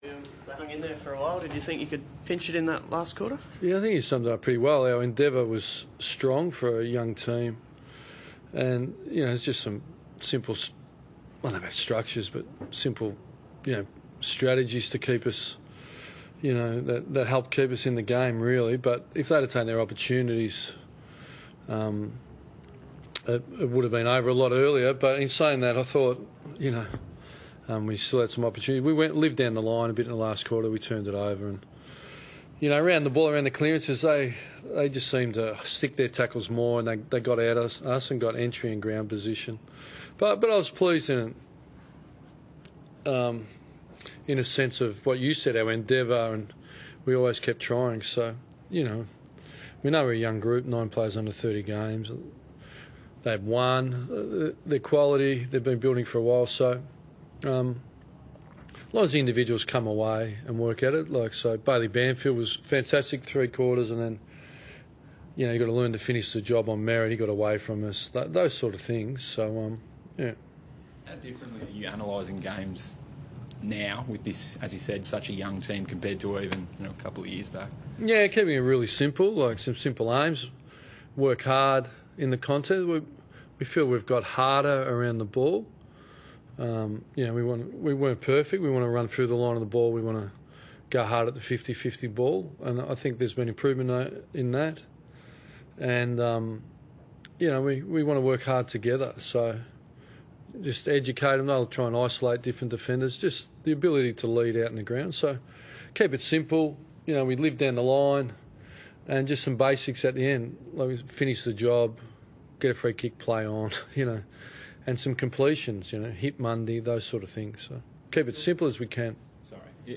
Ross Lyon spoke to media after the round 18 match against Essendon